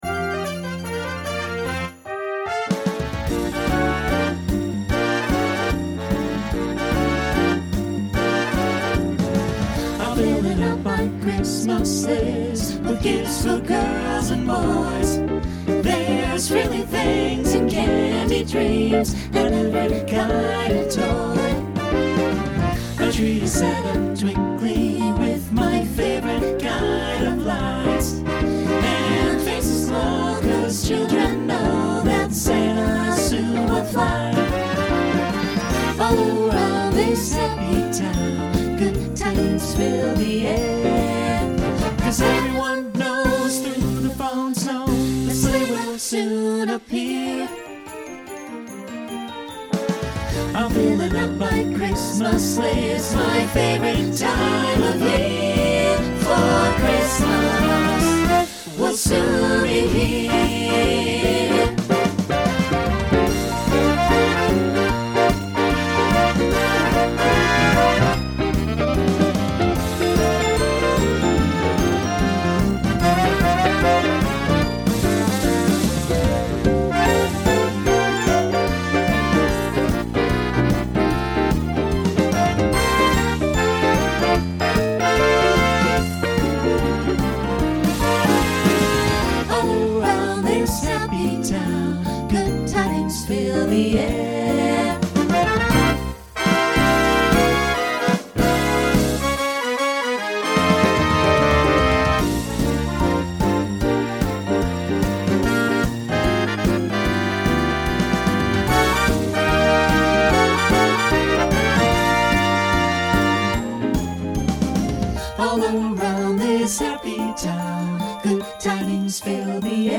Genre Holiday , Swing/Jazz Instrumental combo
Opener Voicing SATB